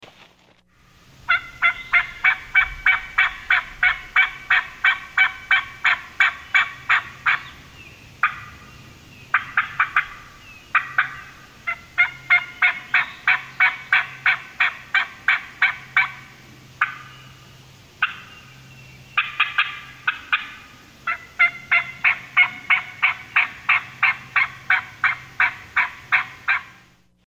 Raspy Young Hen – Turkey Call
The Raspy Young Hen allows you to easily cut and produce raspy, high-pitched yelps.
• Pitch: 7
• Rasp: 9
Lost_Lake_Custom_Turkey_Calls_Raspy_Young_Hen.mp3